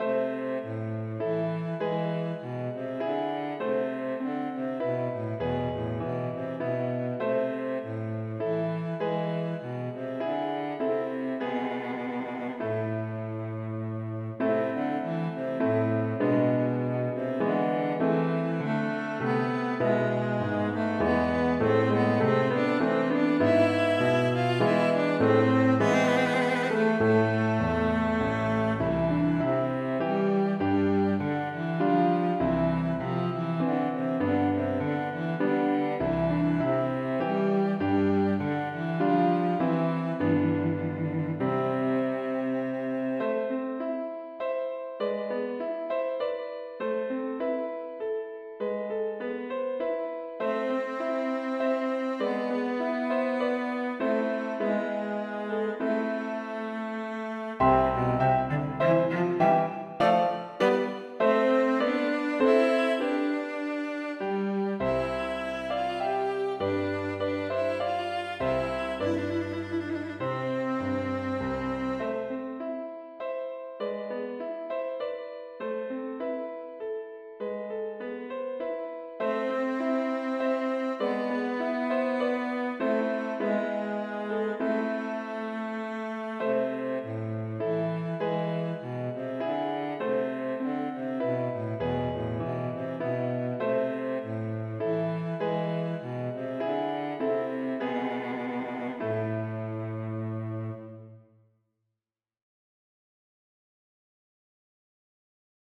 Her er et ganske enkelt stykke for cello og piano. Lydfilene er digitalt produsert i noteprogrammet og er bare illustrasjoner. Lydfilene er tilpasset lytting gjennom hodetelefoner.
- Aamodt 016f: Menuet for cello og piano   Note